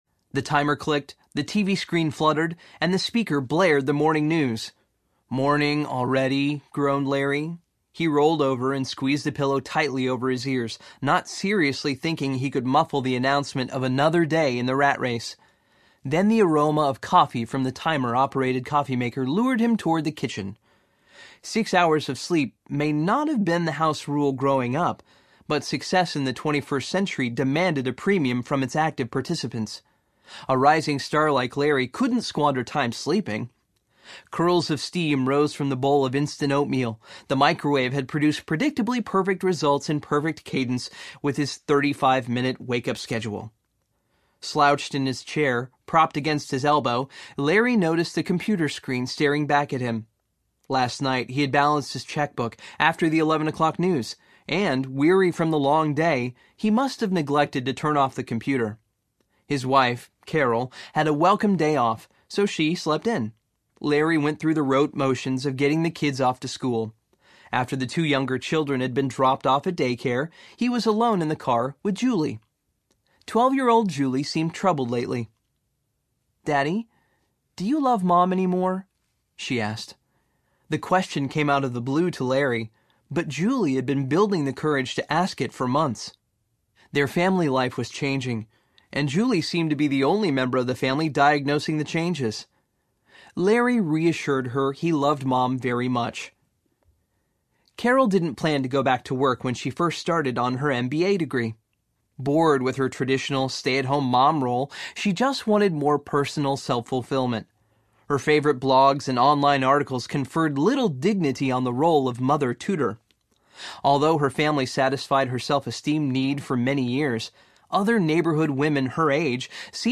The Man in the Mirror Audiobook
Narrator
11.0 Hrs. – Unabridged